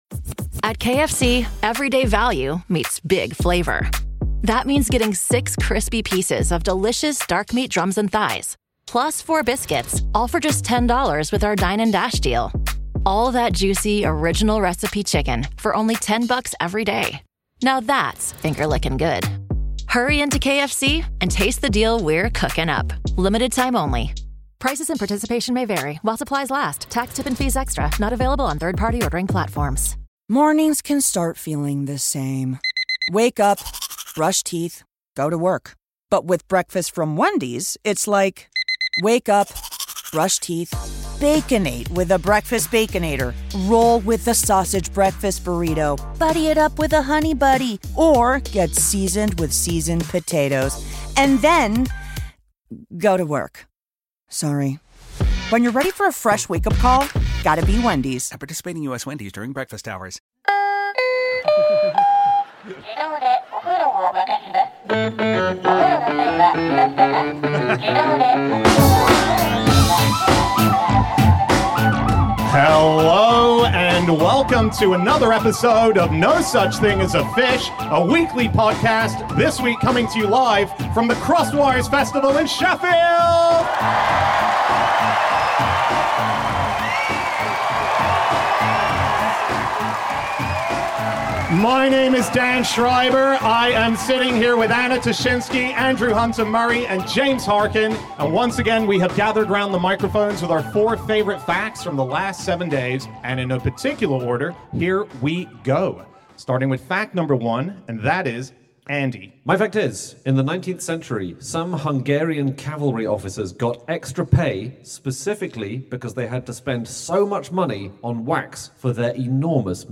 Live from the Crossed Wires Festival